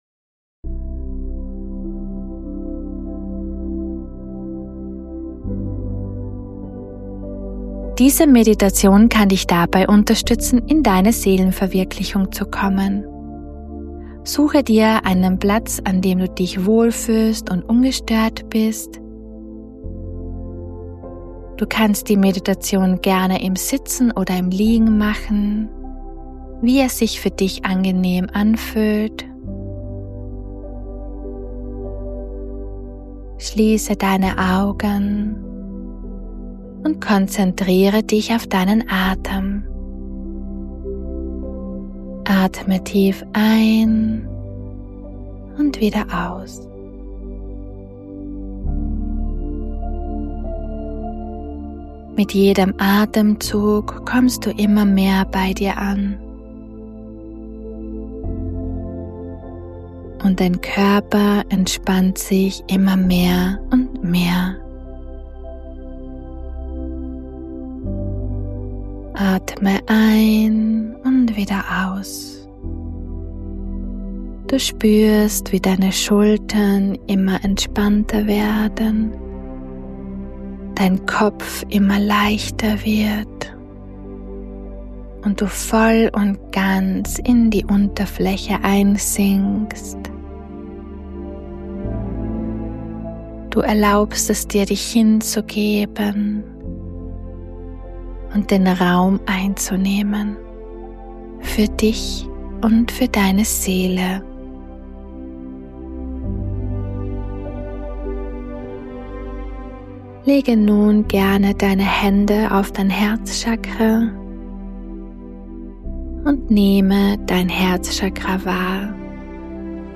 Diese Meditation kann dich dabei unterstützen in deine Seelenverwirklichung zu gehen.